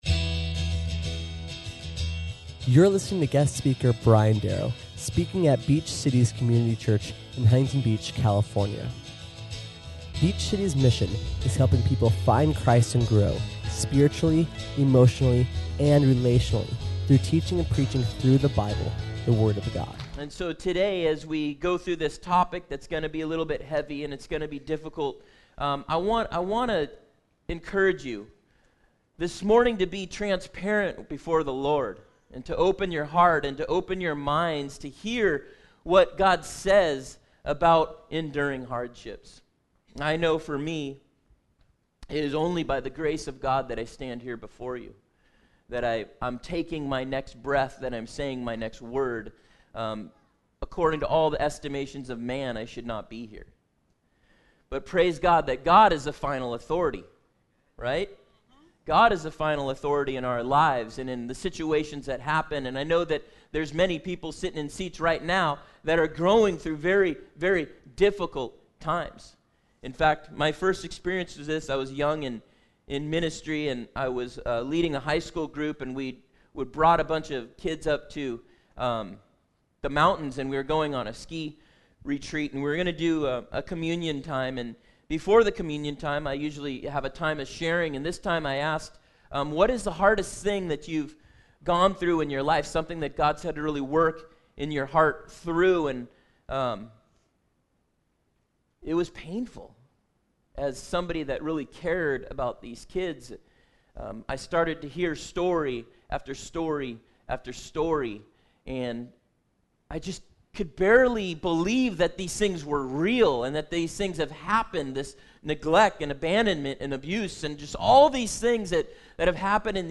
How do we know that we are growing in our relationship with Jesus? This week we learn that one marker that God uses to shape us is by enduring hardships. We learn this week what a hardship is and then three ways that we can withstand hardships. SERMON AUDIO: SERMON NOTES: